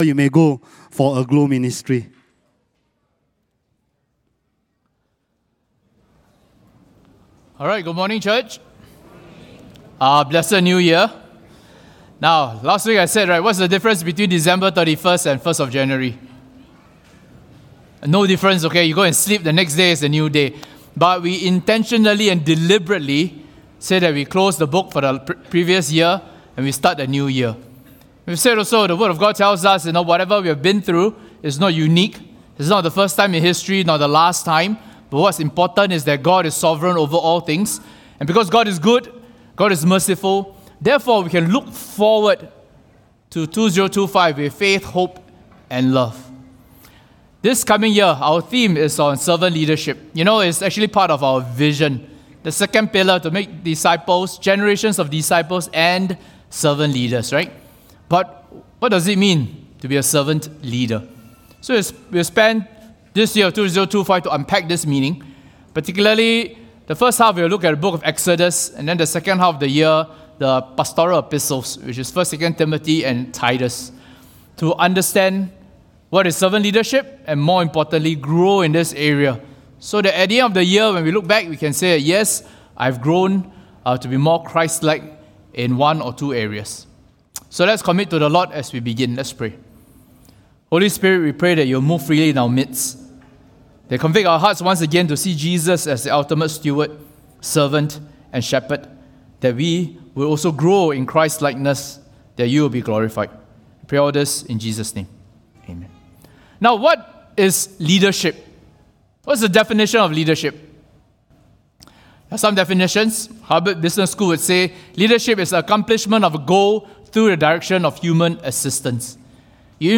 Welcome to Queenstown Baptist Church weekly sermons podcast! Join us each week as we delve into the scripture.